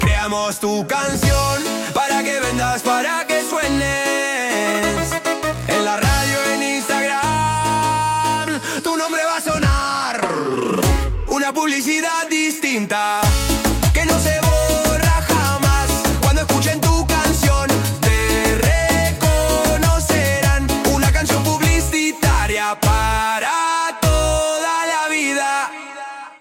Disco moderna
Disco moderna – Voz masculina